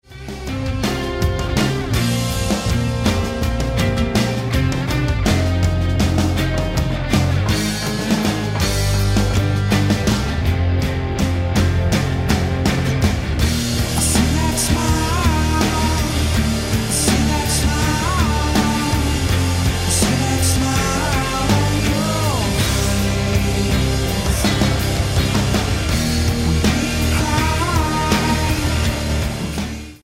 voc, gtr
drums
bass